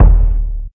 crk_kick.wav